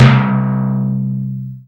prcTTE44019tom.wav